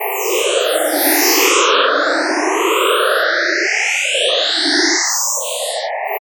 Coagula is a bitmap to sound converter.